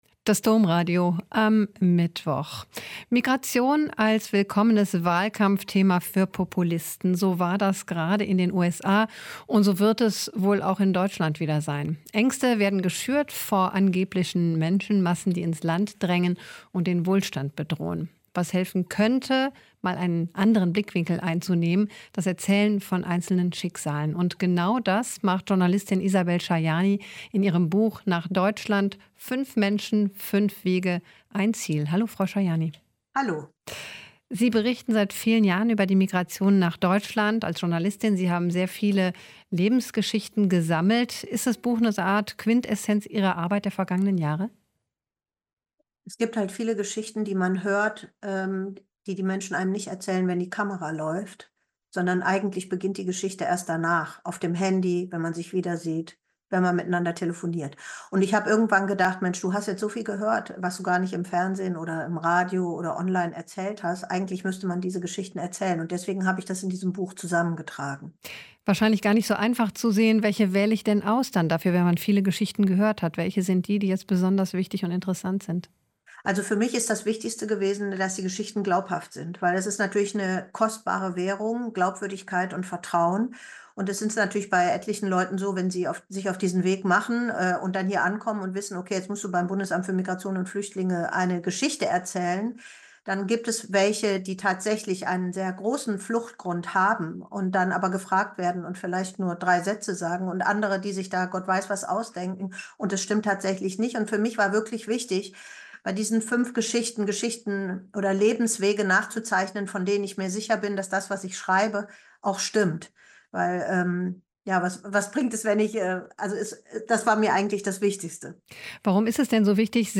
Ein Interview mit Isabel Schayani (Journalistin und Buchautorin)